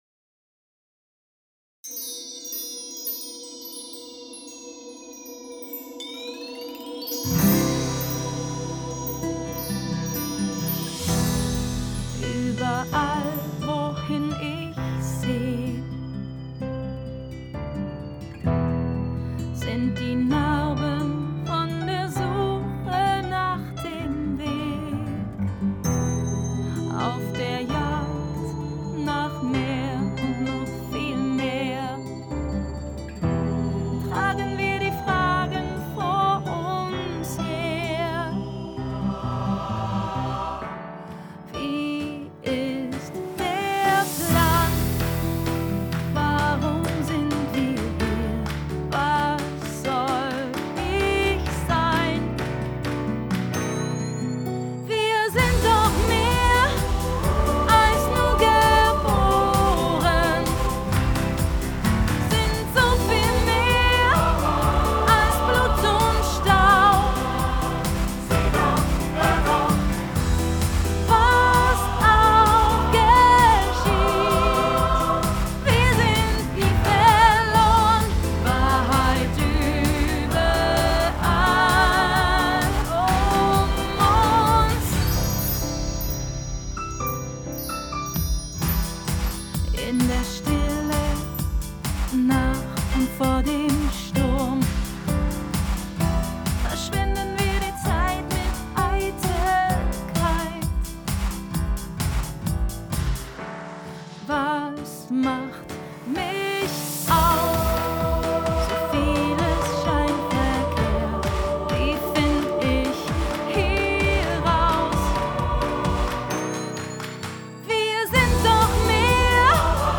This is the mono mix with just some EQ applied ,want to get some feed back on the over all balance .